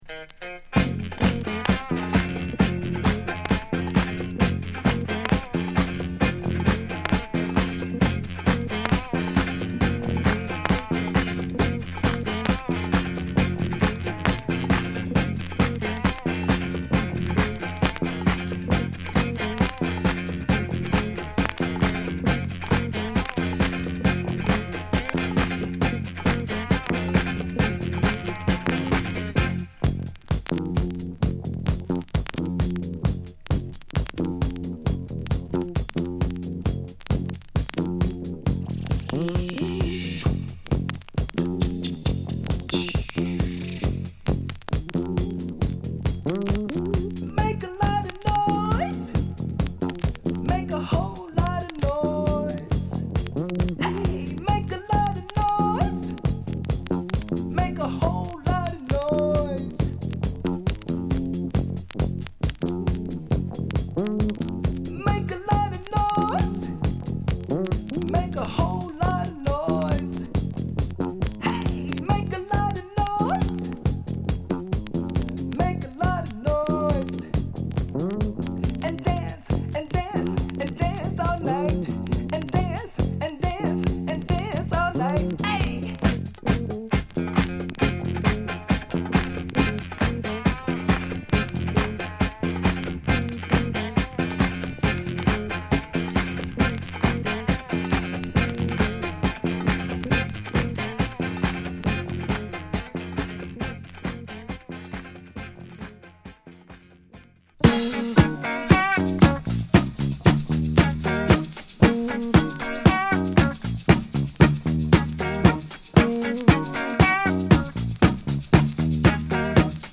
贅肉をそぎ落としたシンプルかつファットなダンストラックを披露！
＊A1擦れ／傷多し